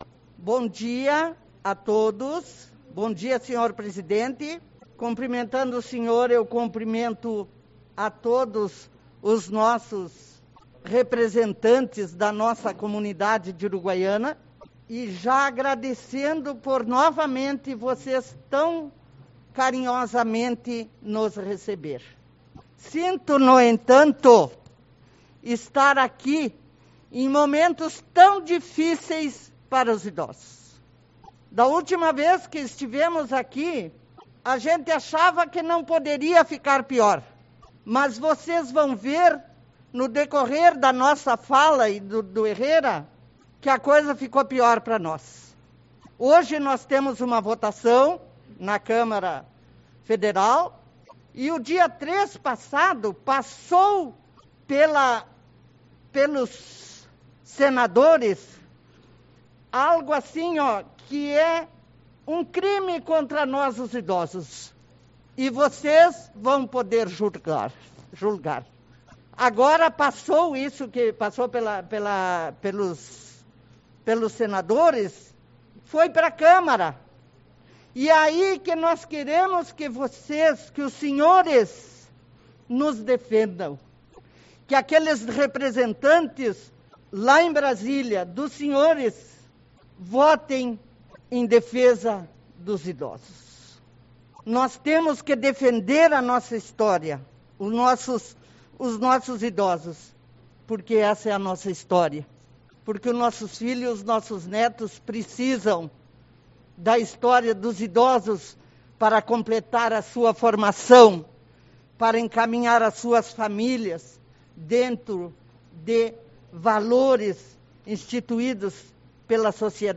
09/03 - Reunião Ordinária